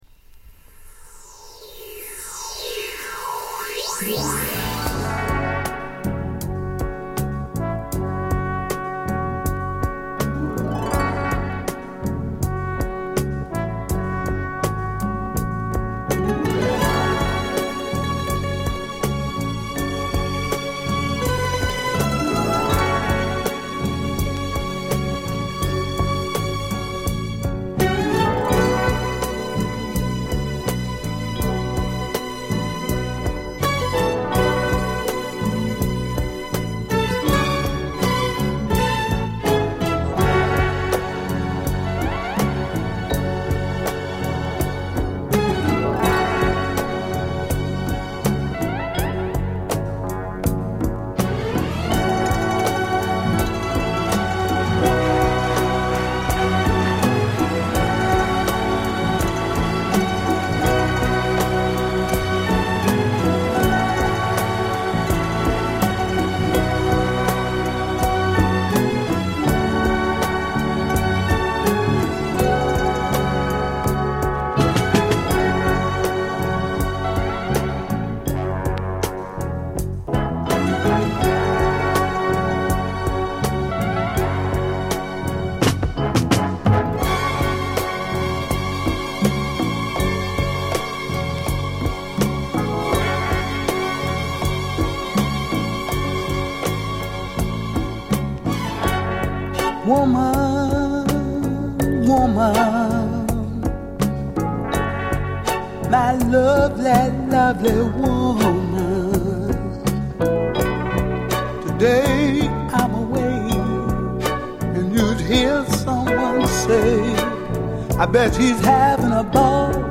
高揚感溢れるA3